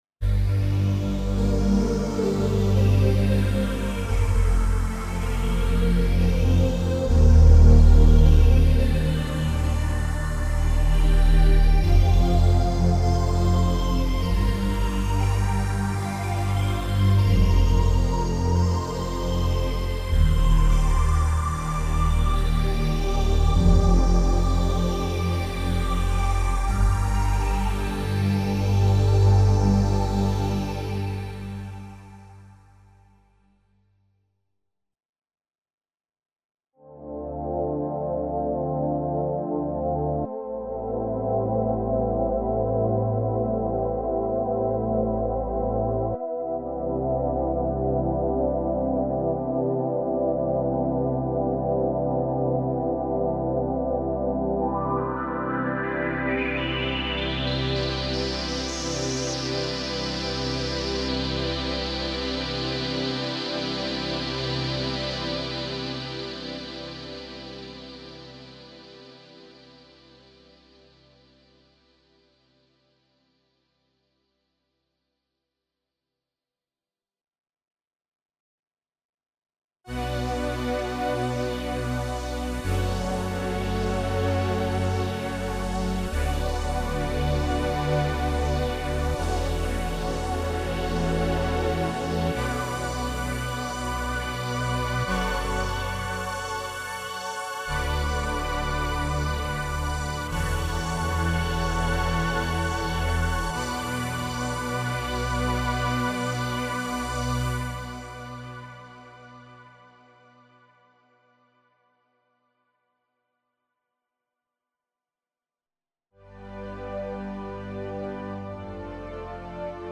string-pad-demo-radias.mp3